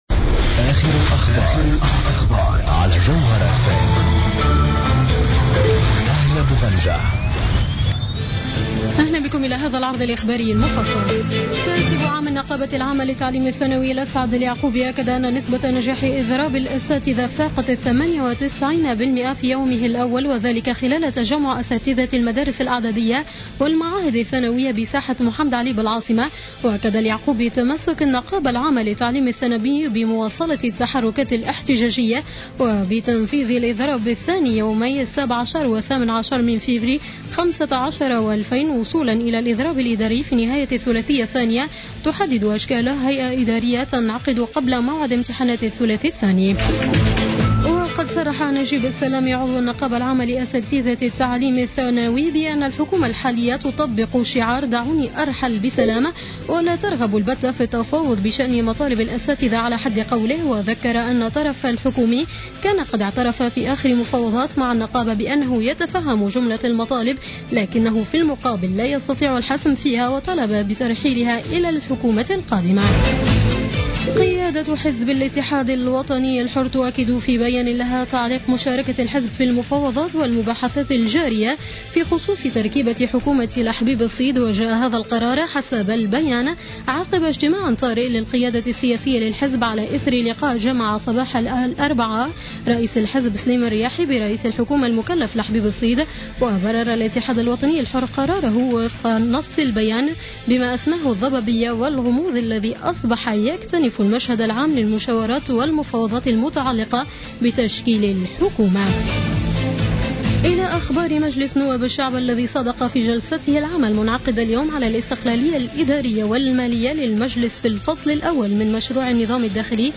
نشرة أخبار السابعة مساء ليوم الاربعاء 21-01-15